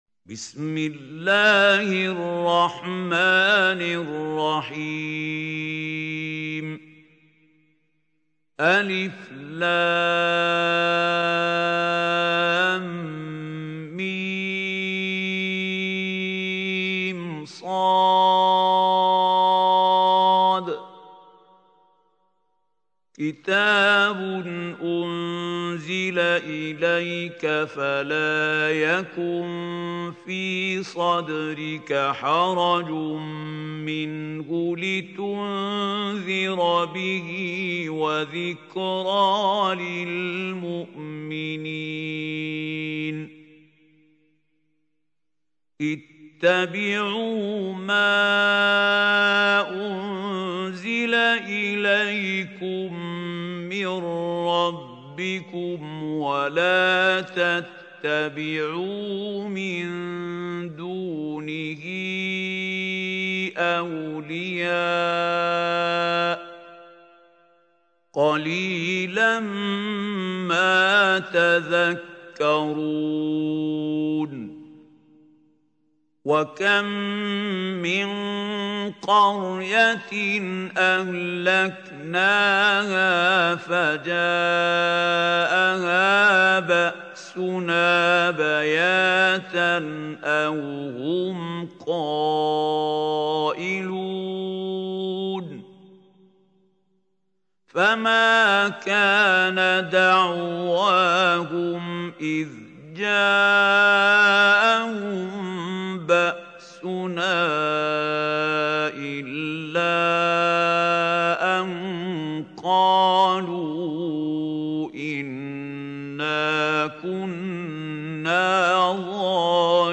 سورة الأعراف | القارئ محمود خليل الحصري